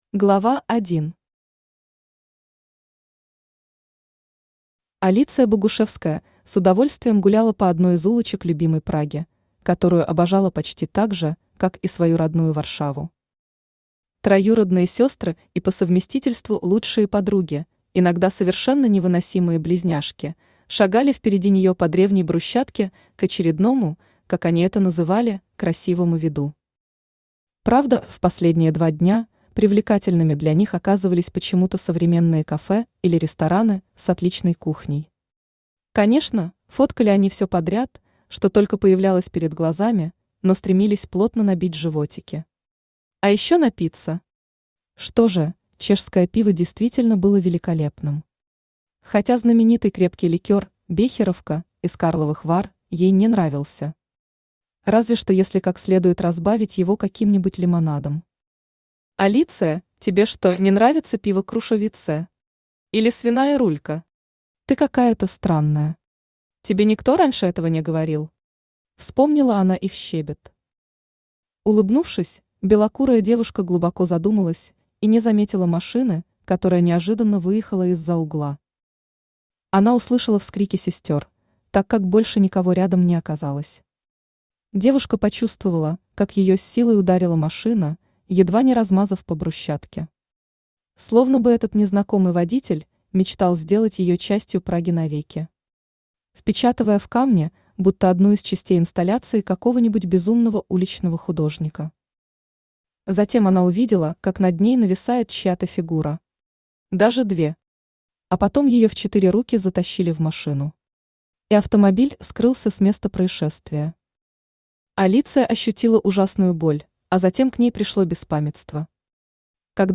Aудиокнига Время для вампиров Автор Кристина Воронова Читает аудиокнигу Авточтец ЛитРес.